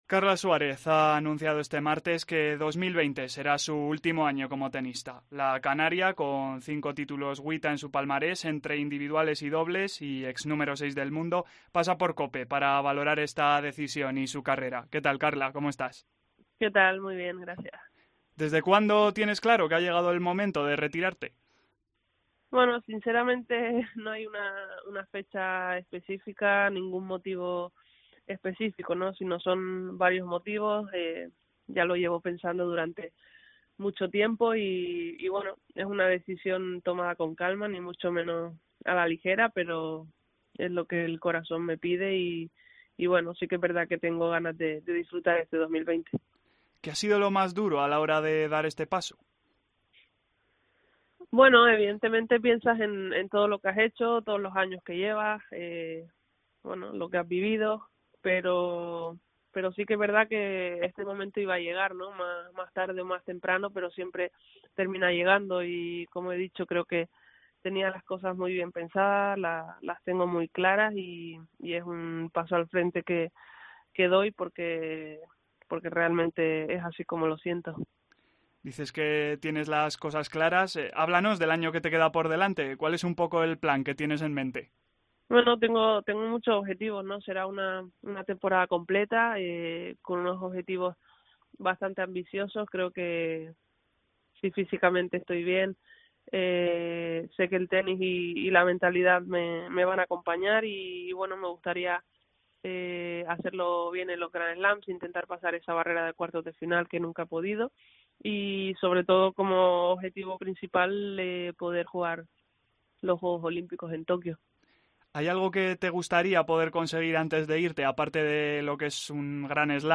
Entrevistamos a la tenista canaria, que valora tanto la decisión de retirarse al final del próximo año como su carrera